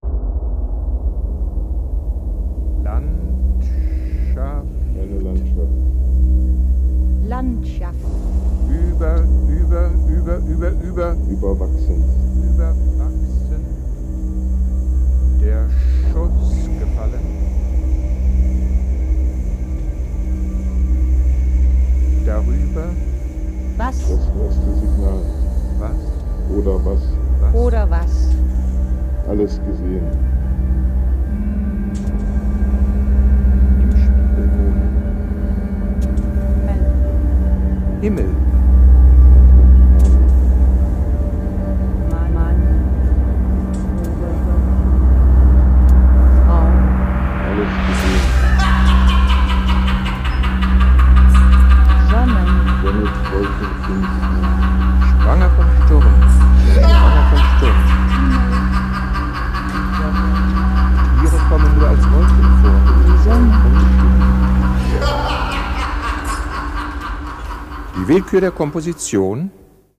short composition
a three-channel piece with live voice